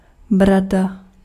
Ääntäminen
Ääntäminen France: IPA: [baʁb] Haettu sana löytyi näillä lähdekielillä: ranska Käännös Ääninäyte Substantiivit 1. vous {m} Muut/tuntemattomat 2. brada {f} Suku: f .